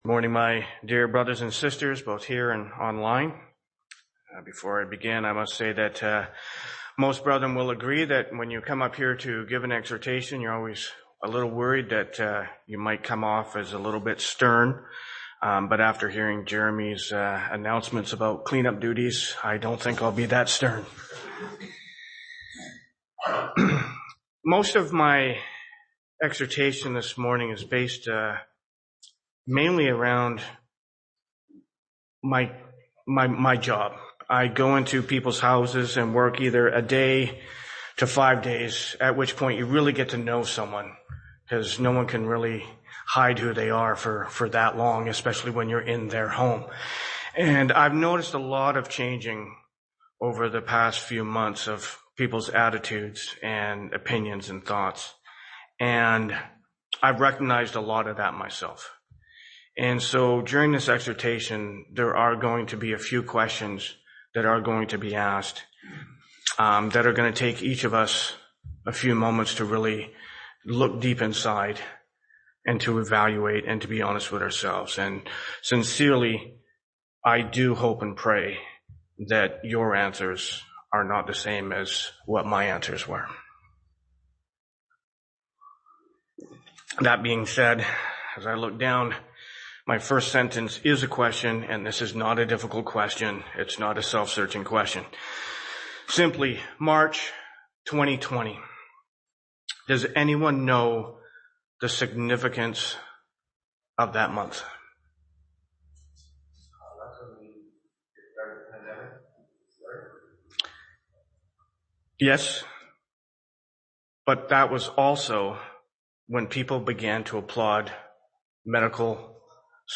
Exhortation 04-03-22